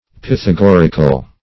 Search Result for " pythagorical" : The Collaborative International Dictionary of English v.0.48: Pythagoric \Pyth`a*gor"ic\, Pythagorical \Pyth`a*gor"ic*al\, a. [L. Pythagoricus, Gr.
pythagorical.mp3